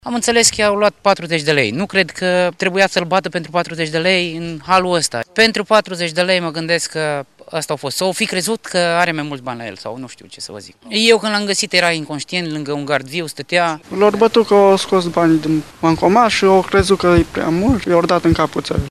VOX-URI.mp3